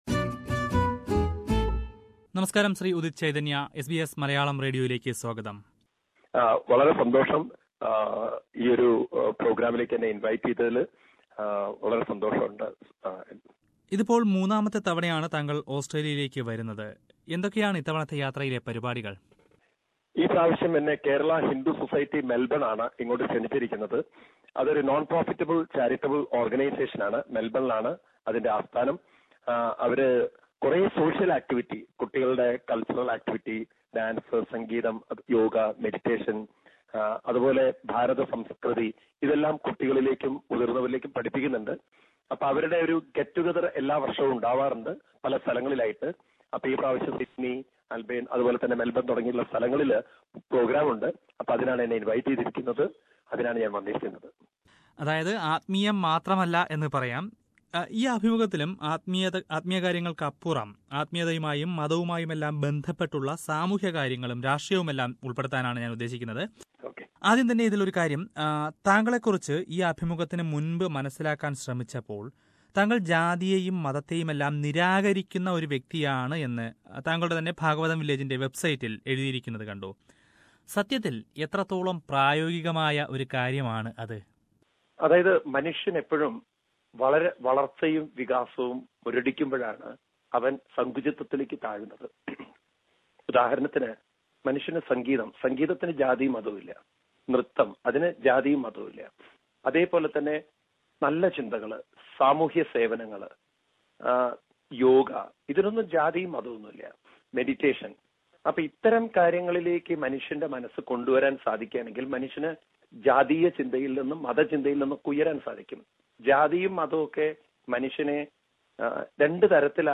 സംഭാഷണം